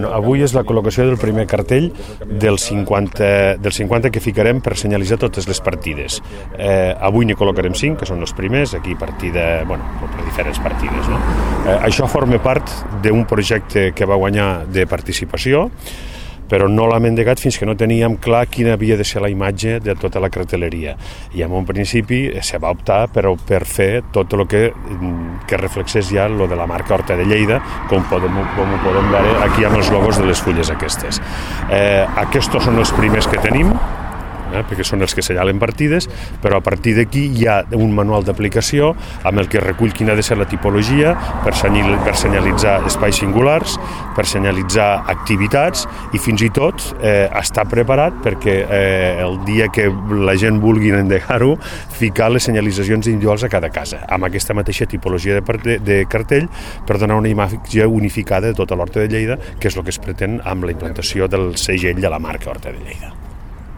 tall-de-veu-del-regidor-joan-queralt-sobre-la-nova-senyalitzacio-a-lhorta-de-lleida